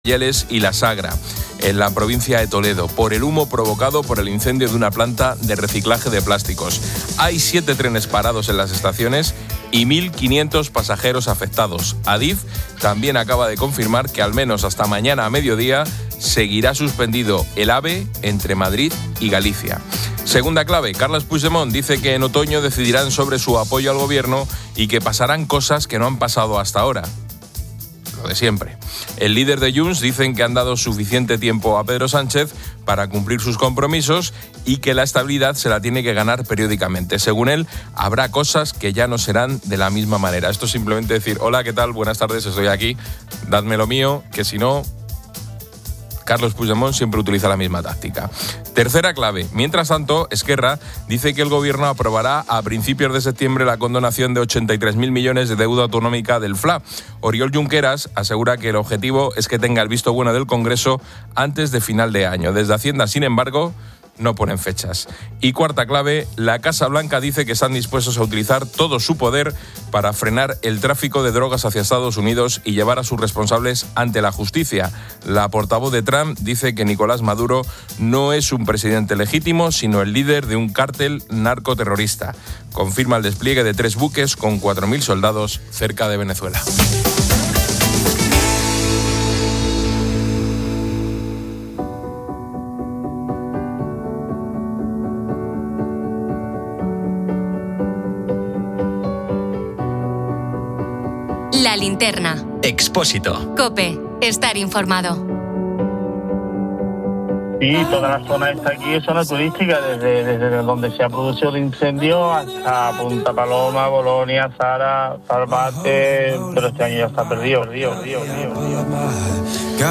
El audio comienza con un resumen de noticias: un incendio en una planta de reciclaje en Toledo afecta a trenes y suspende el AVE Madrid-Galicia.